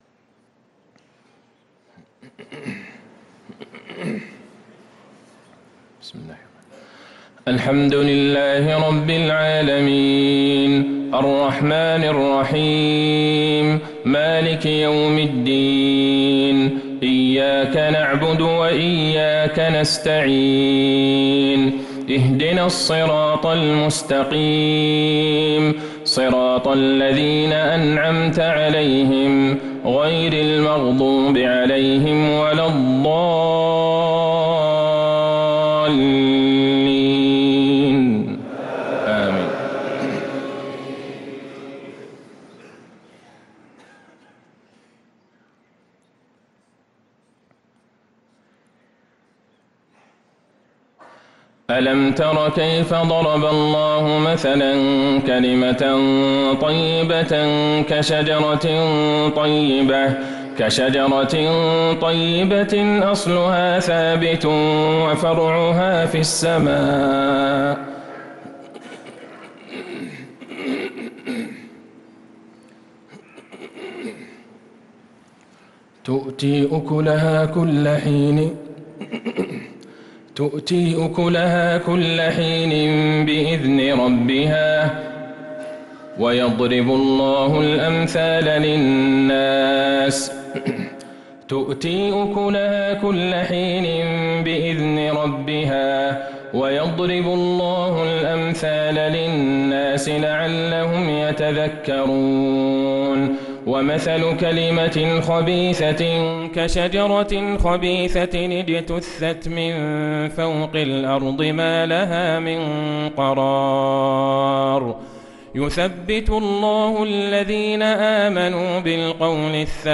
صلاة العشاء للقارئ عبدالله البعيجان 19 ذو الحجة 1444 هـ
تِلَاوَات الْحَرَمَيْن .